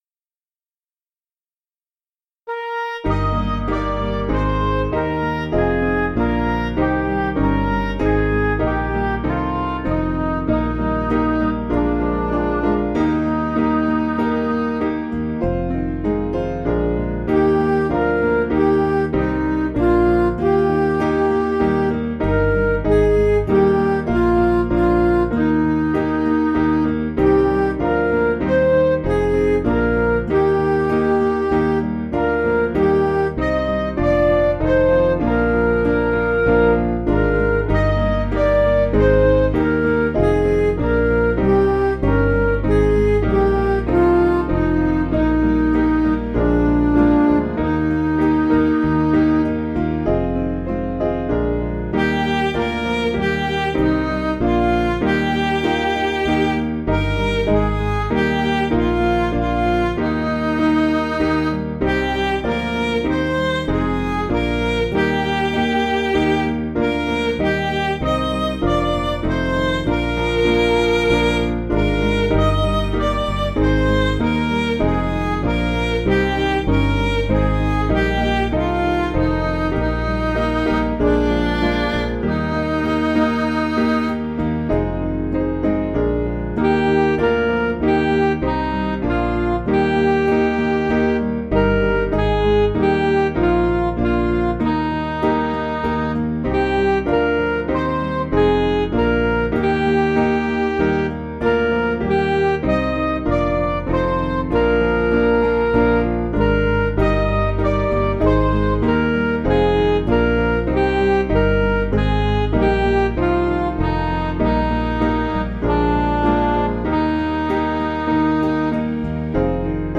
Piano & Instrumental
(CM)   6/Eb